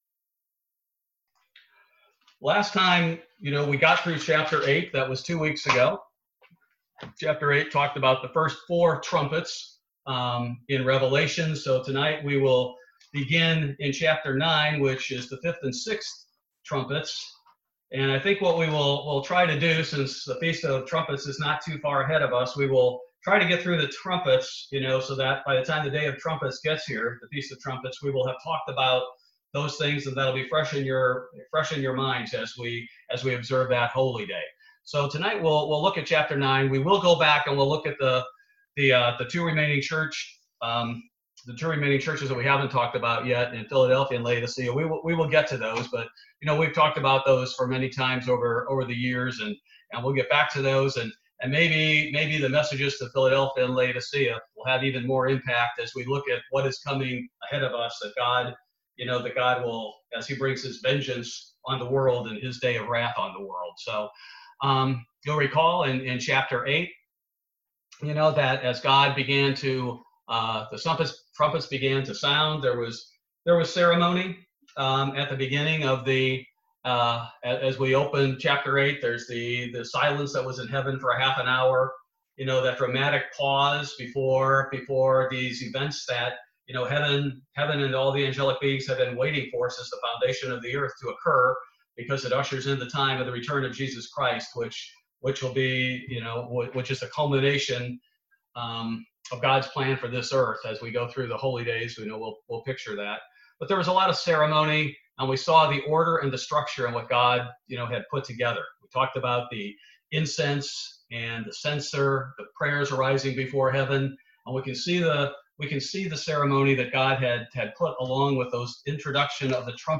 Weekly Bible Study. This Bible Study focuses primarily on Revelation 9 and the 5th and 6th trumpets.